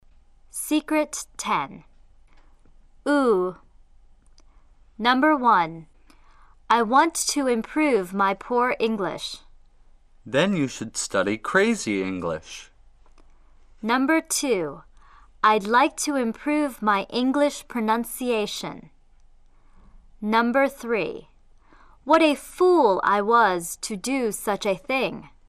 Nickname外号：小圆唇长“乌”音。类似火车长鸣音“呜”。